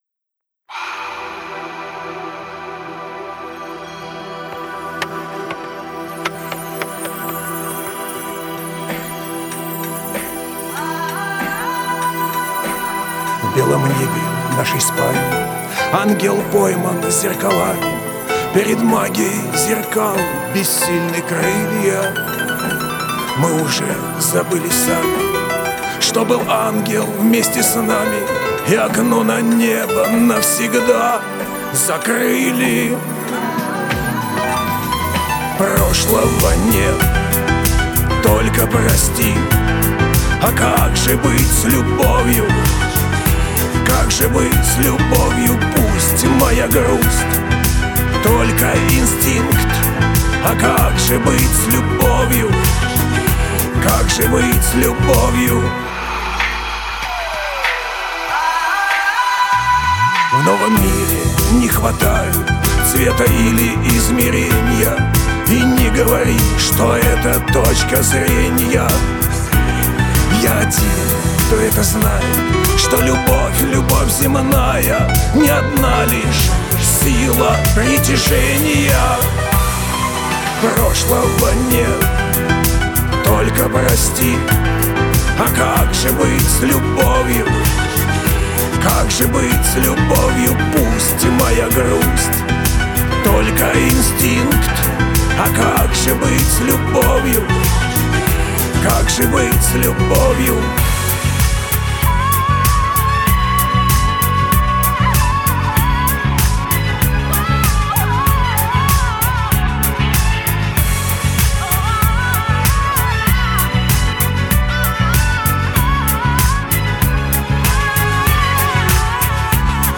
твой знакомый хрипловатый голос дарит тепло:)